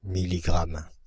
Ääntäminen
Ääntäminen Paris: IPA: [mi.li.ɡʁam] France (Île-de-France): IPA: /mi.li.ɡʁam/ Haettu sana löytyi näillä lähdekielillä: ranska Käännös Substantiivit 1. милиграма Suku: m .